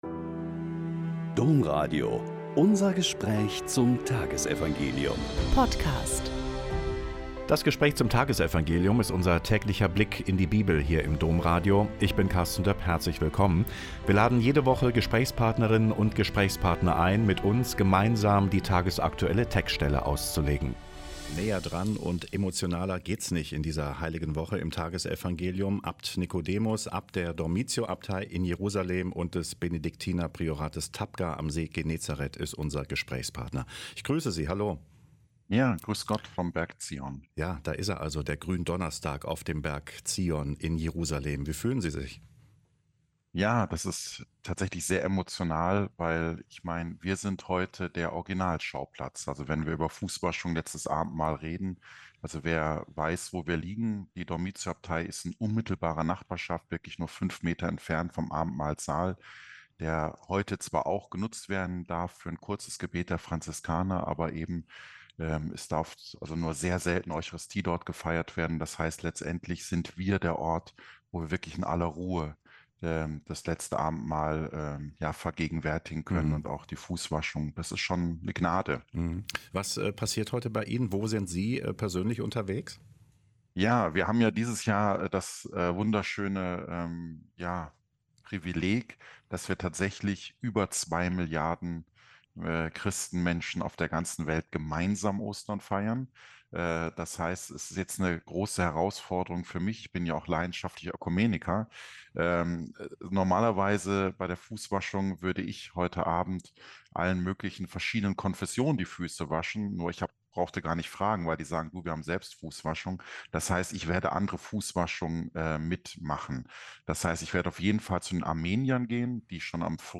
Joh 13,1-15 - Gespräch Abt Nikodemus Schnabel OSB